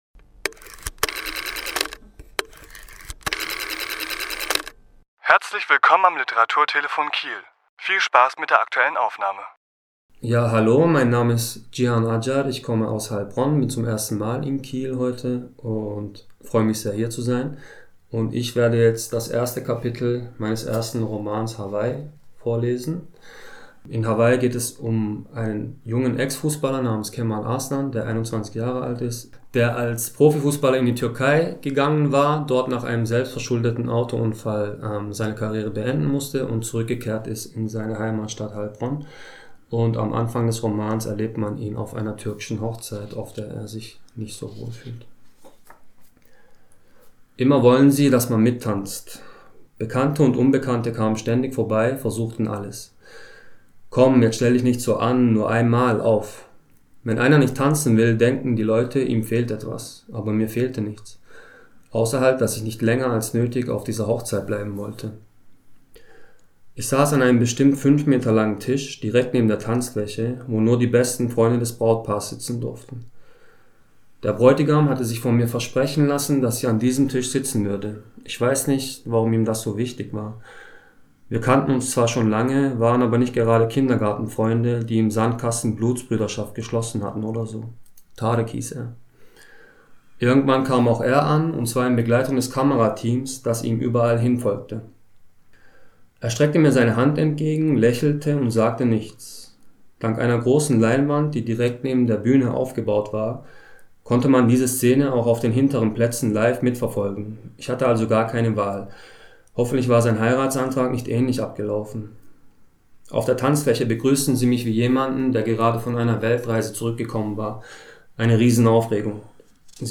Autor*innen lesen aus ihren Werken
Die Aufnahme entstand im Rahmen der Lese-Lounge im Literaturhaus Schleswig-Holstein am 20.10.2021.